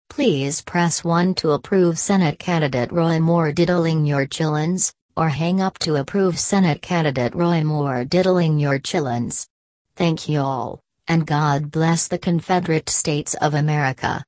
Stubhill News has secured an exclusive first look at the audio content of the robocall requesting permission for Moore to engage in sexual acts with your children.
robocall.mp3